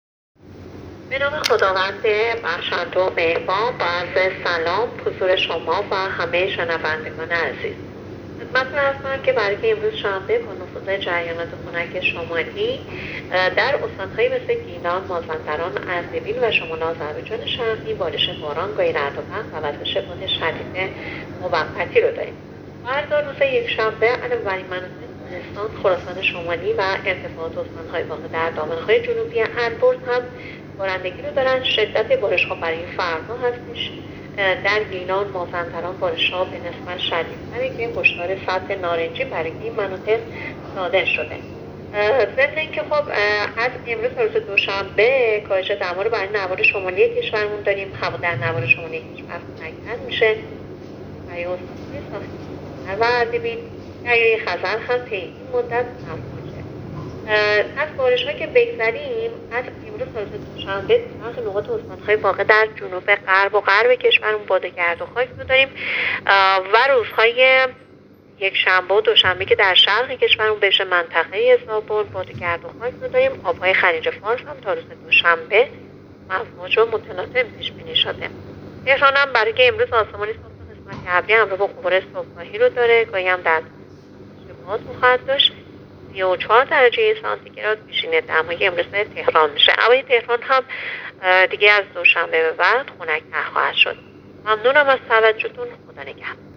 گزارش رادیو اینترنتی پایگاه خبری از آخرین وضعیت آب‌وهوای دوم مهرماه؛